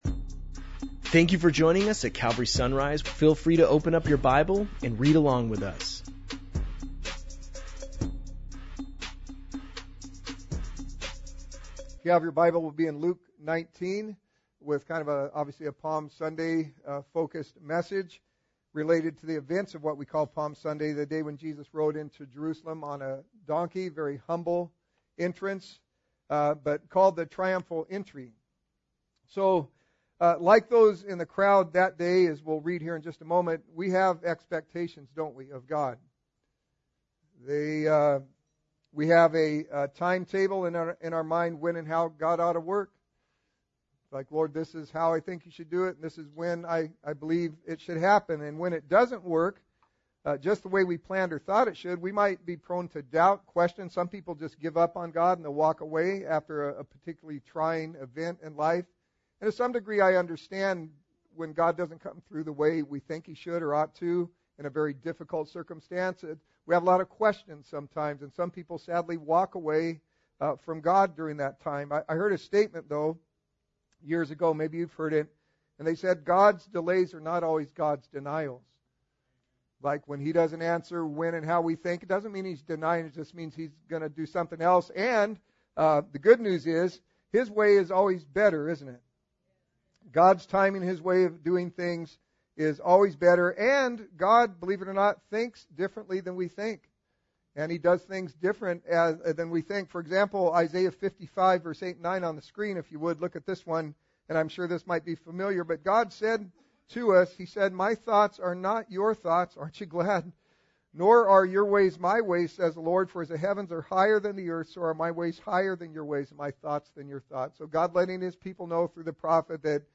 Palm-Sunday-Luke-19-audio.mp3